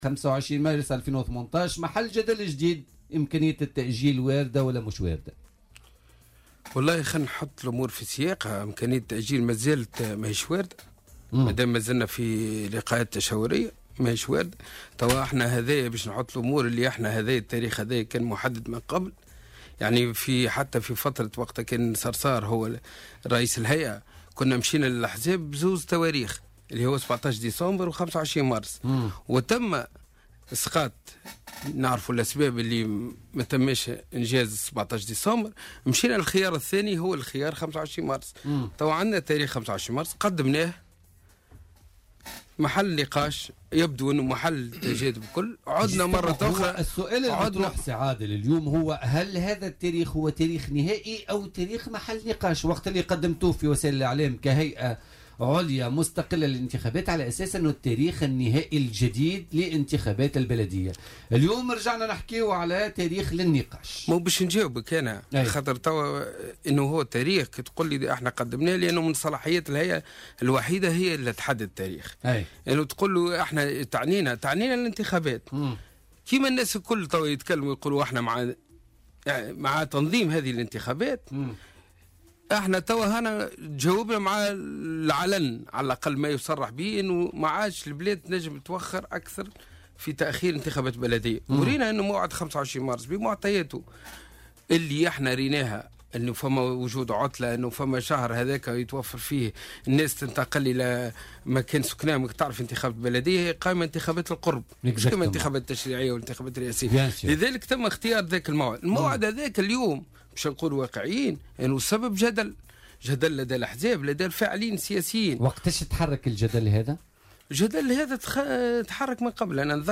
وأضاف في مداخلة له اليوم في برنامج "بوليتيكا" اليوم أن الهيئة هي الطرف الوحيد المخول له تحديد موعد الانتخابات، لكن أبوابها تبقى مفتوحة للنقاش.